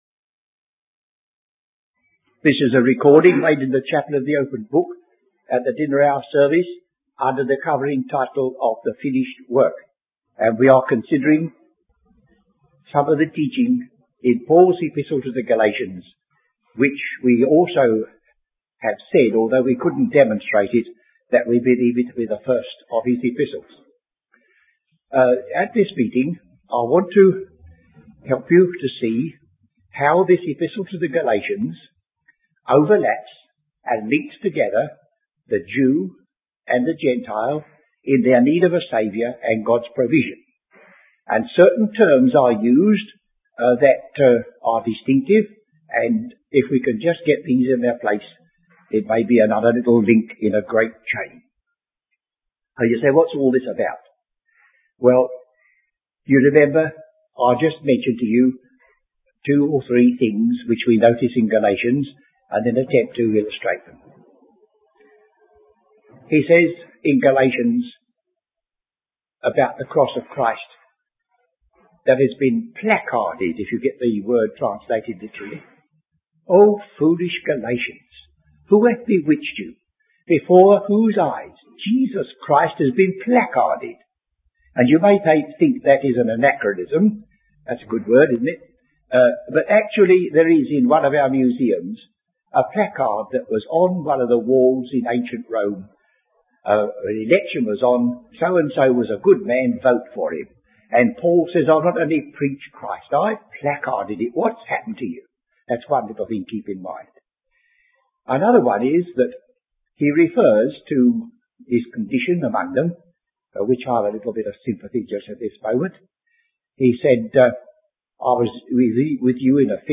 Teaching